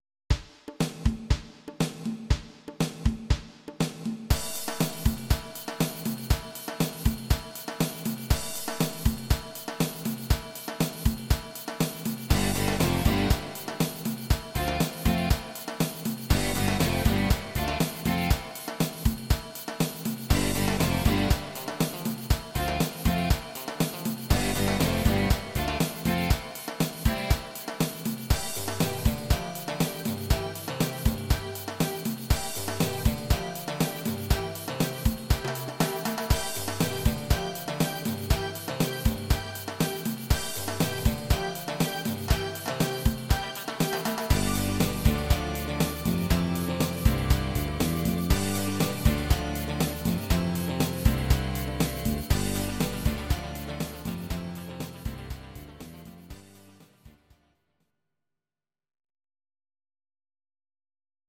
Audio Recordings based on Midi-files
Our Suggestions, Pop, 1980s